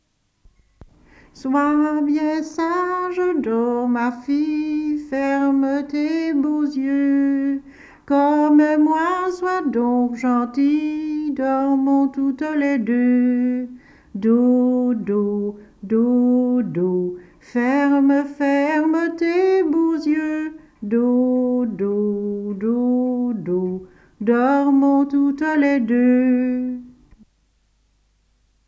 Genre : chant
Type : enfantine
Lieu d'enregistrement : Nessonvaux
Support : bande magnétique